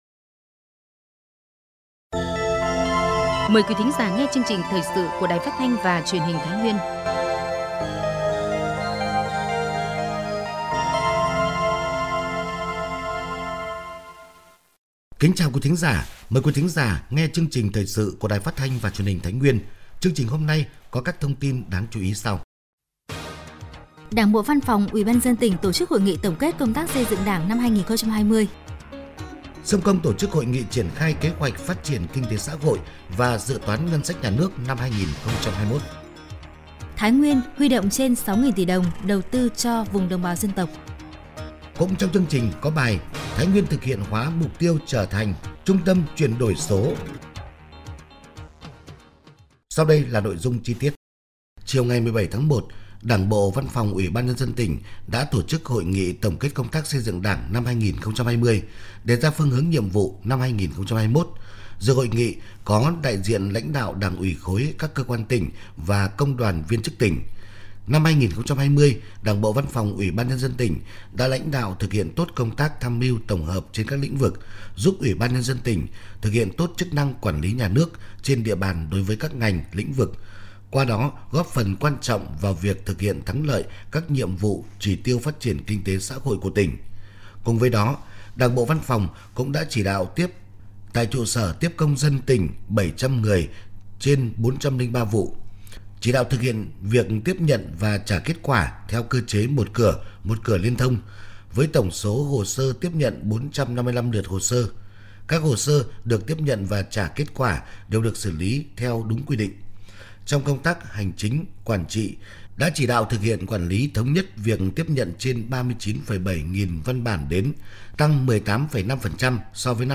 Thời sự tổng hợp Thái Nguyên ngày 21/1/2021
PHAT_THANH.mp3